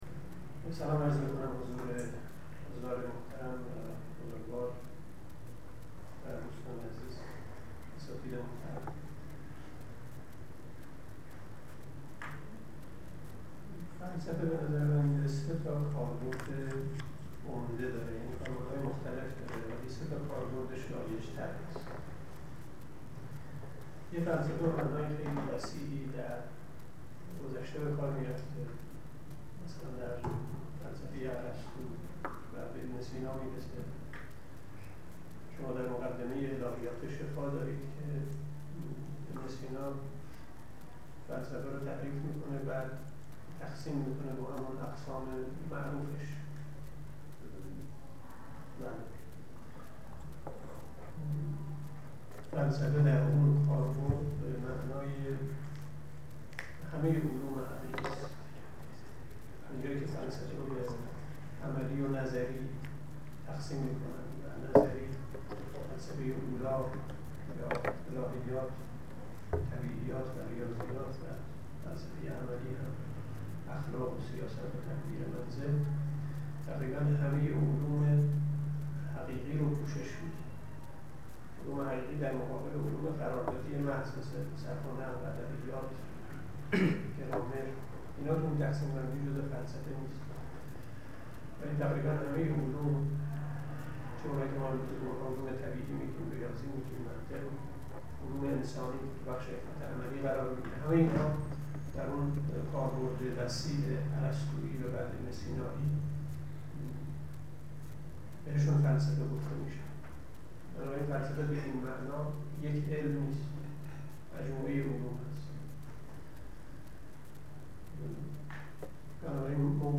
سخنرانی
است که در خانه اندیشمندان علوم انسانی ایران در تاریخ ۲۶/۰۱/۱۳۹۲ ایراد شده است.